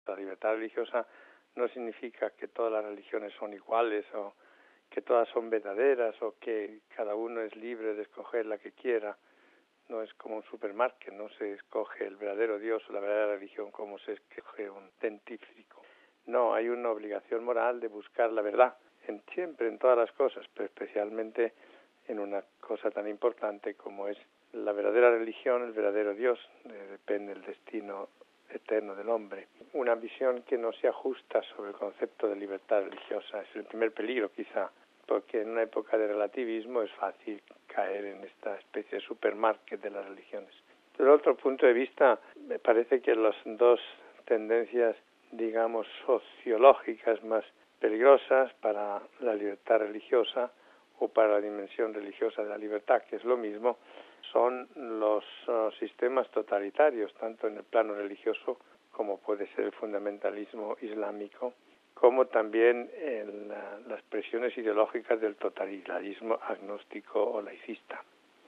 Es lo que se puso de relieve durante la conferencia «La dimensión religiosa de la libertad humana», organizada por el Instituto Acton, el 14 de marzo, en la Universidad Pontificia Santa Cruz de Roma.
Oigamos al purpurado español.